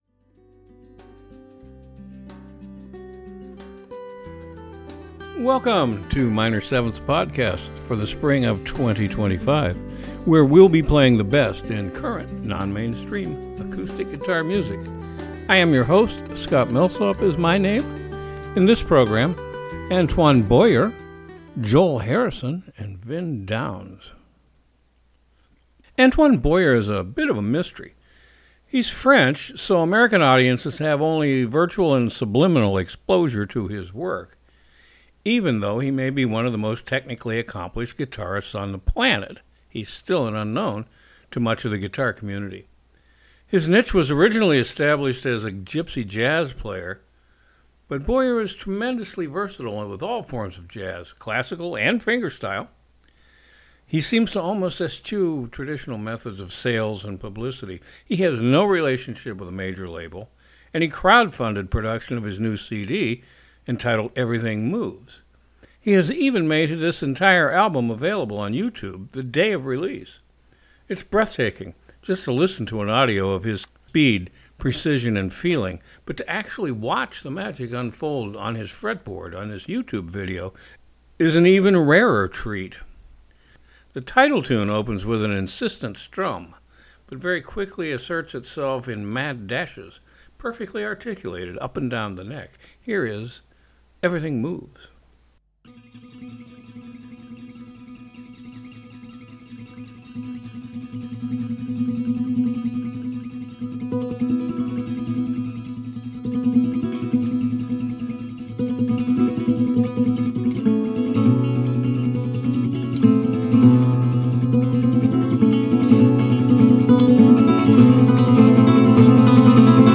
Acoustic guitar music out of the mainstream